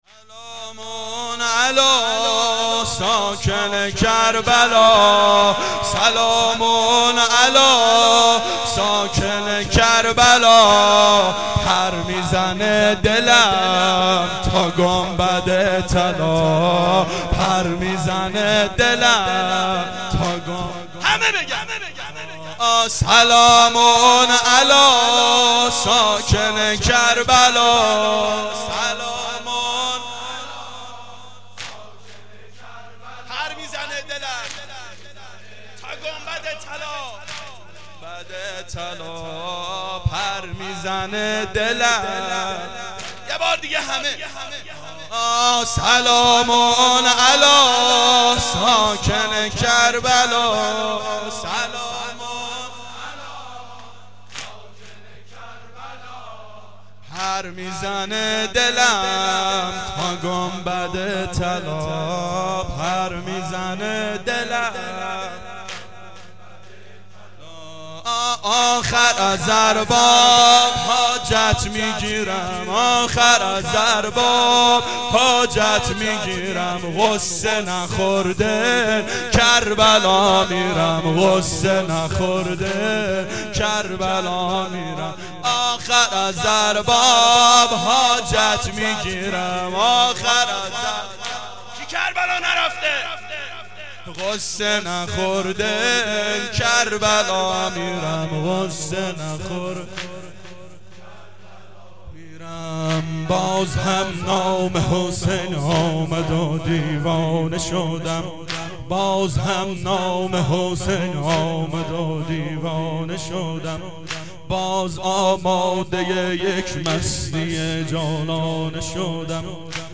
شب سوم محرم 89 گلزار شهدای شهر اژیه